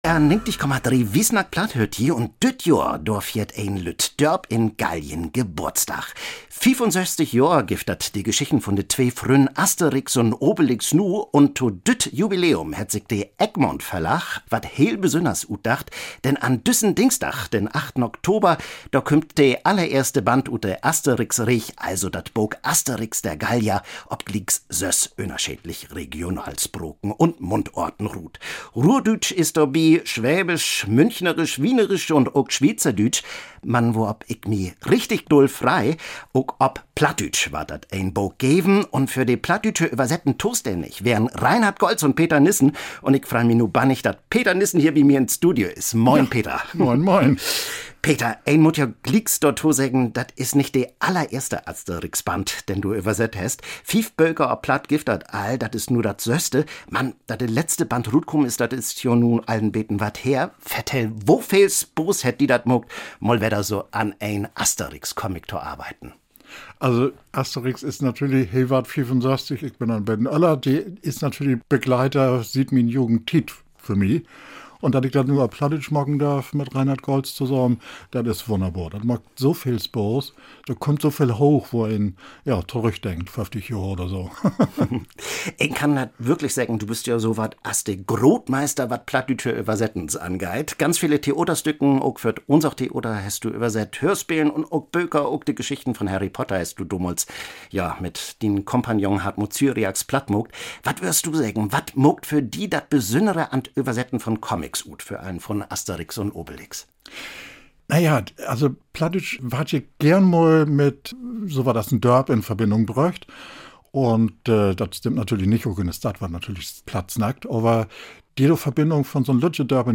Un de Radiolüüd hebbt ook Besöök in’t Studio hatt !